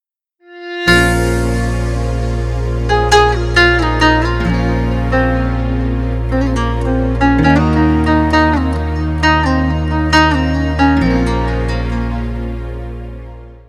• Качество: 320, Stereo
гитара
грустные
без слов